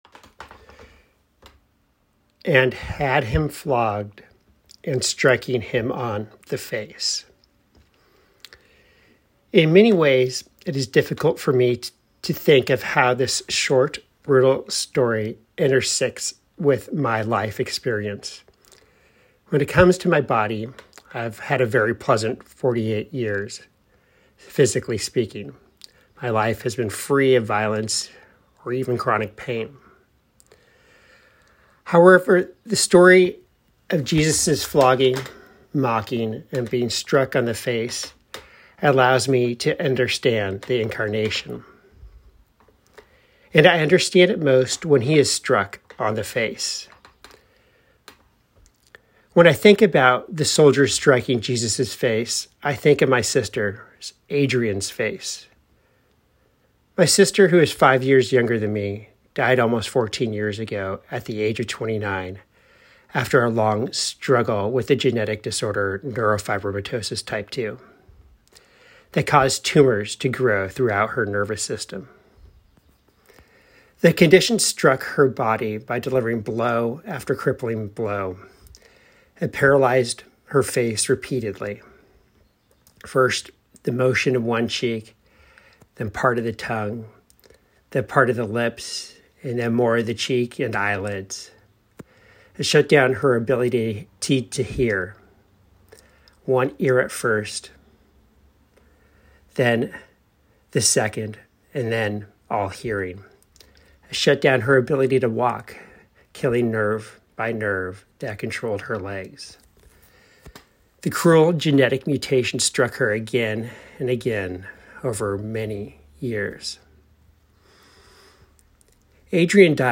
Good Friday Reflections